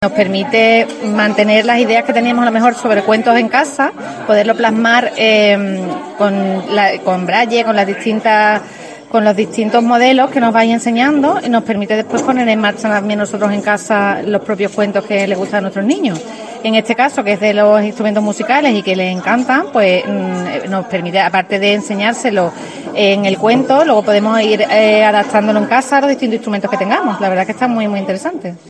A su lado, otra de las participantes